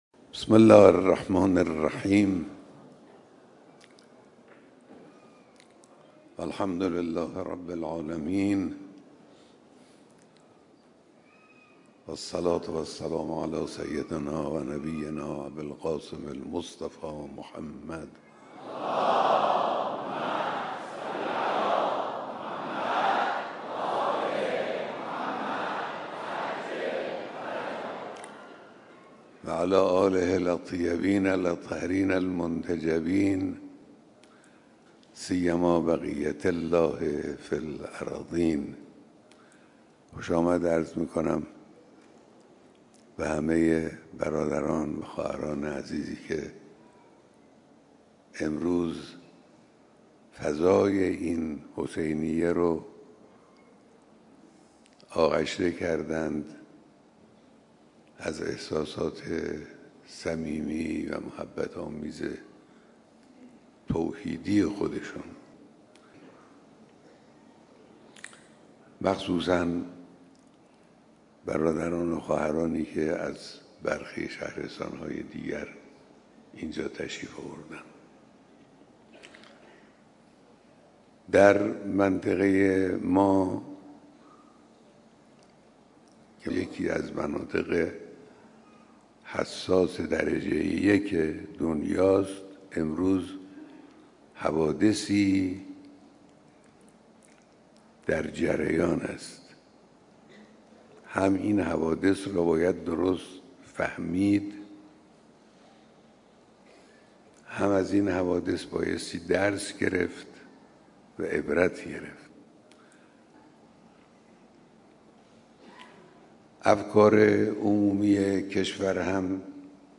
صوت کامل بیانات صبح امروز رهبر انقلاب در دیدار هزاران نفر از اقشار مختلف مردم درباره تحولات منطقه
هزاران نفر از اقشار مختلف مردم صبح امروز (چهارشنبه) با حضور در حسینیه امام خمینی(ره) با حضرت آیت‌الله خامنه‌ای رهبر معظم انقلاب اسلامی دیدار کردند. حضرت آیت‌الله خامنه‌ای در این دیدار درباره تحولات اخیر منطقه سخنرانی کردند.